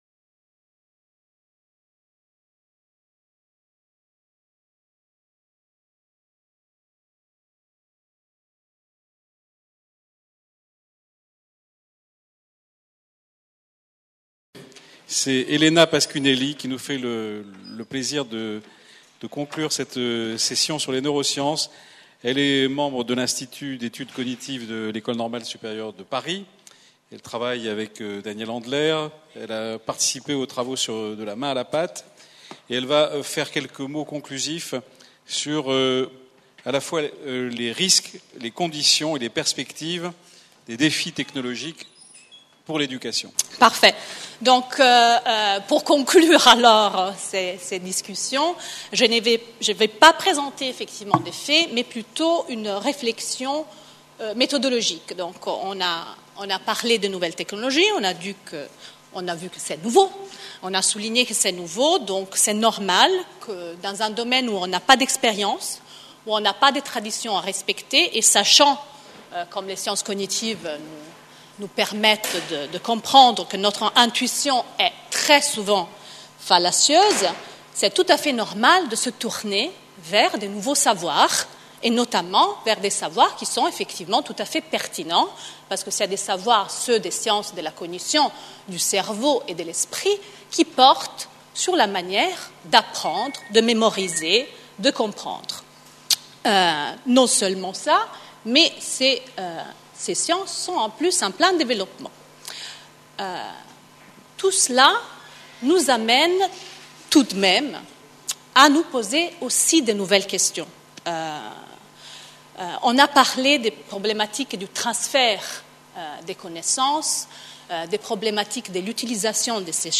PREMIER SÉMINAIRE INTERNATIONAL SANKORÉ DE RECHERCHE UNIVERSITAIRE SUR LA PÉDAGOGIE NUMÉRIQUE Conférence-Débat : INSERM / SANKORE : ZOOM SUR LES NEUROSCIENCESQue disent les neurosciences sur l’Education numérique ? Les nouveaux médias viennent concurrencer les outils traditionnels de la transmission des savoirs et du développement des capacités cognitives.Dans un domaine où l'innovation est constante, la recherche devient rapidement obsolète.